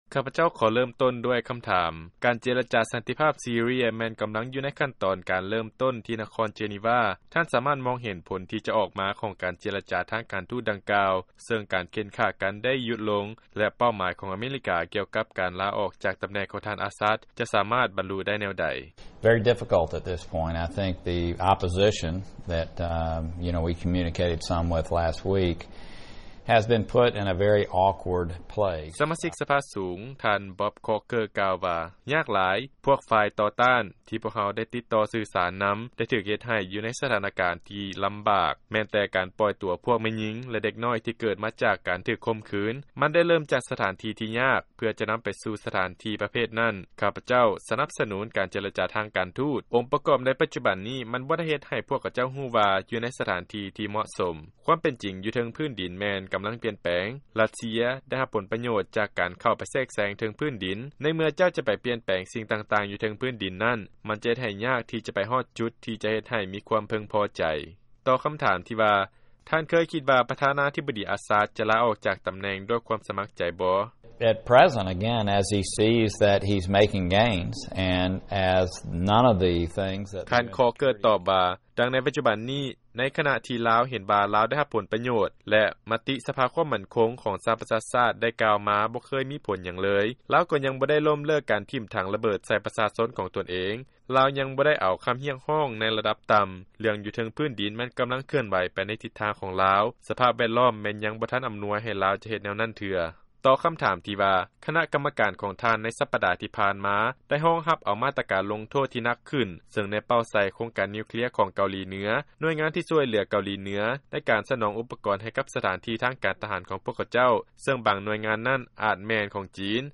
ຟັງລາຍງານ ການສຳພາດ ປະທານຄະນະກຳມະການ ພົວພັນສາກົນ ສະມາຊິກສະພາສູງ